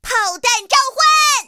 T-60开火语音1.OGG